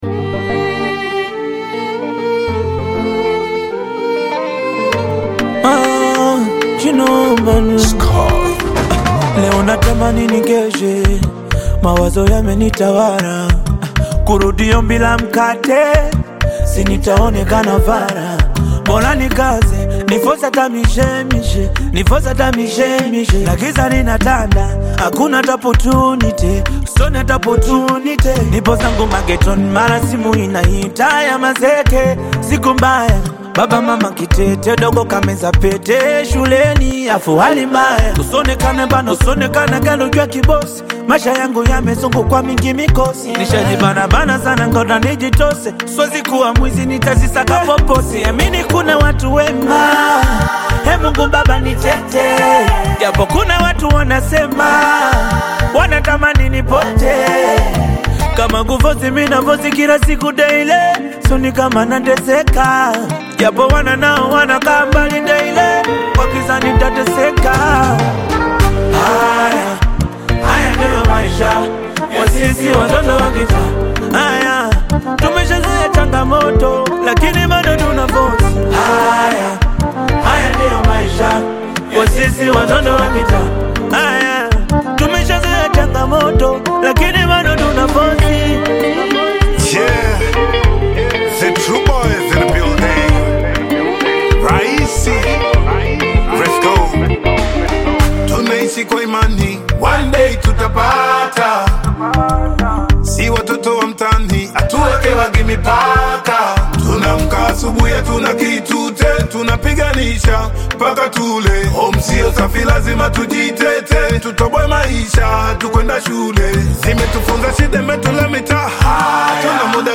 is a powerful Bongo Flava/Afro-Beat single
Genre: Bongo Flava